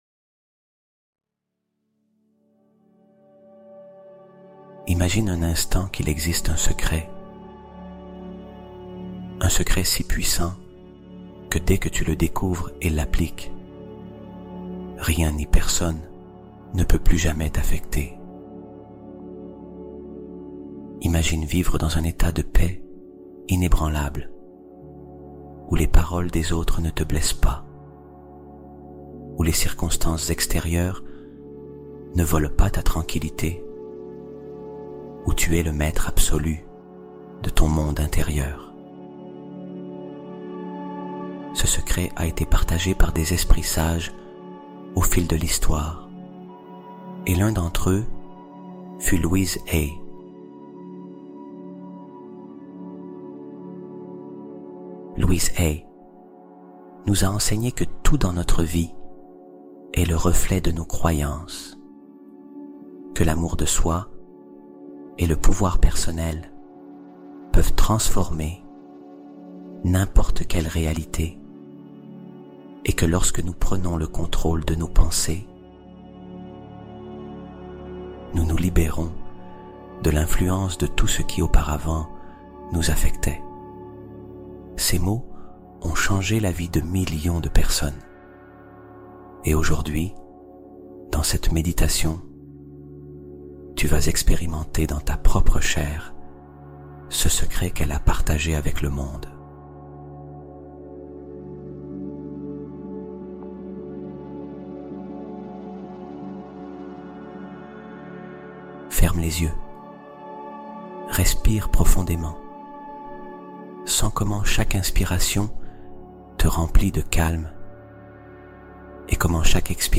Méditation Guidée